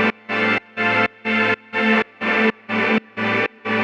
Index of /musicradar/sidechained-samples/125bpm